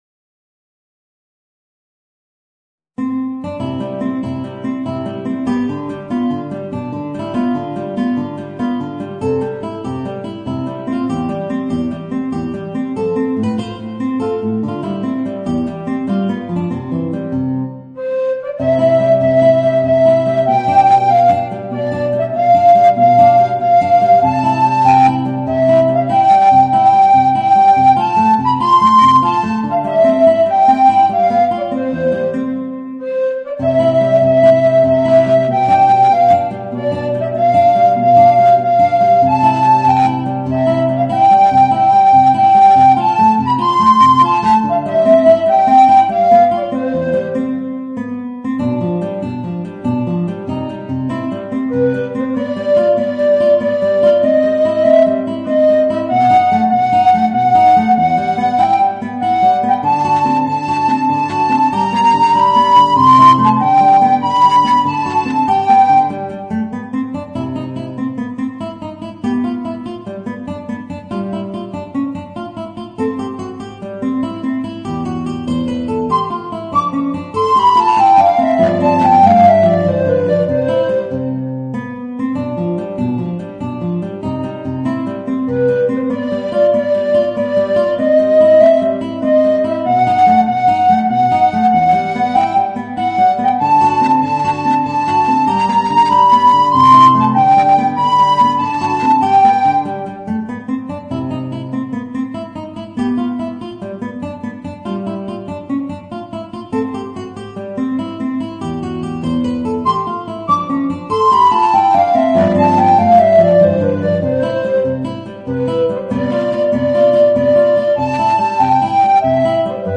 Voicing: Guitar and Alto Recorder